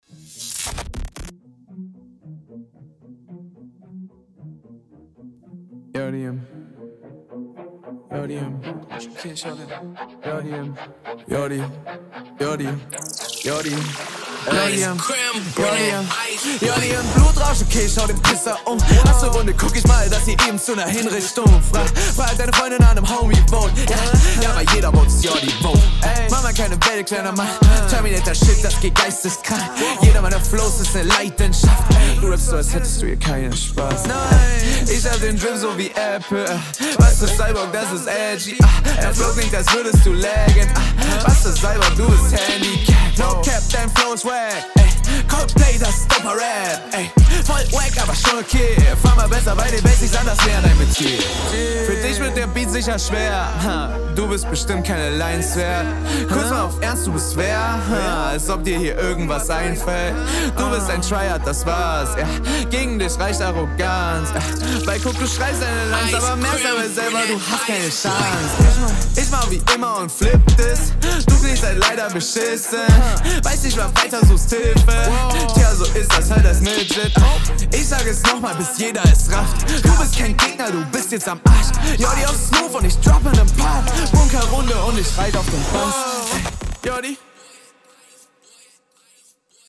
Typischer Trapstyle, aber die Runde hat mich nicht vom Hocker gehauen.